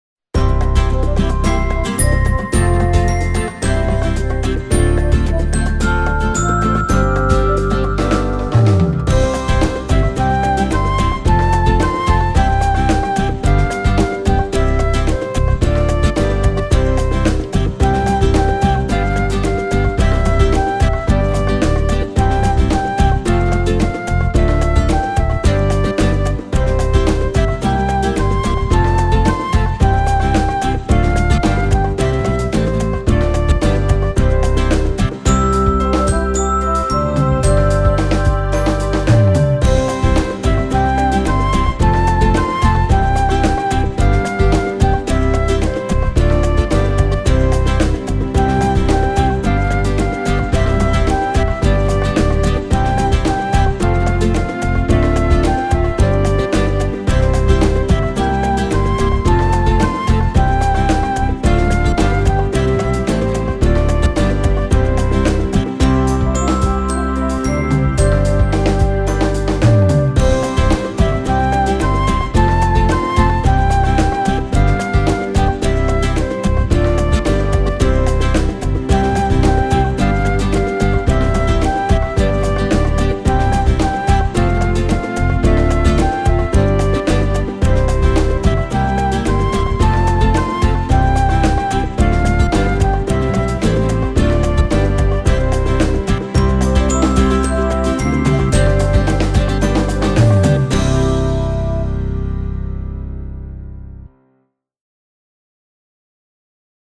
für die Ukulele